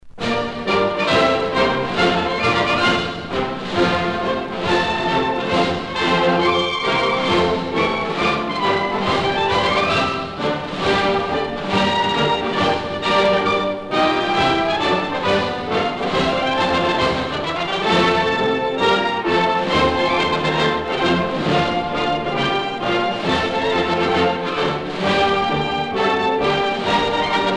gestuel : à marcher
circonstance : militaire
Catégorie Pièce musicale éditée